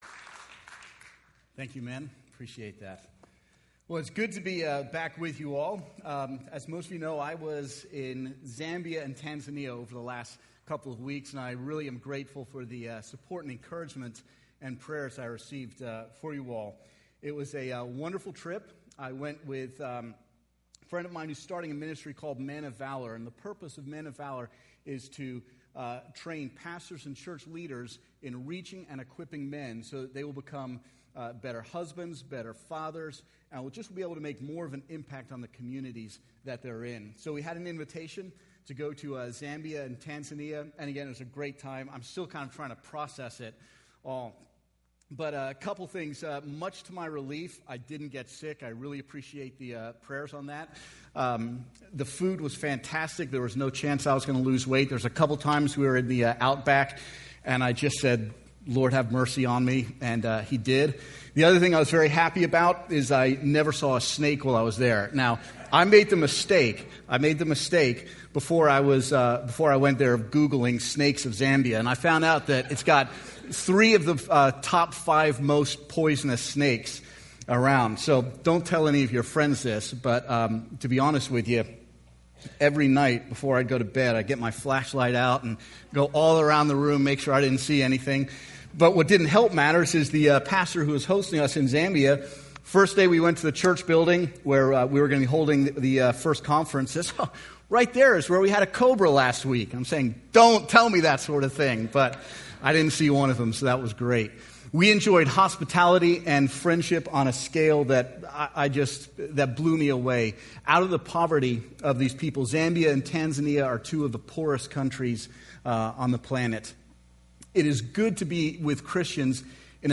10:30 Service
Sermon